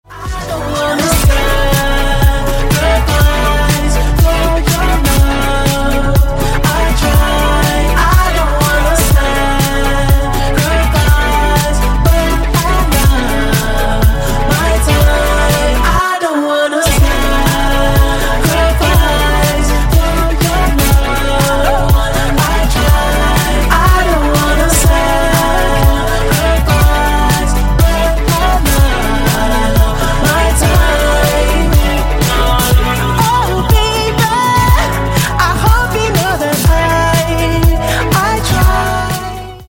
R&B Soul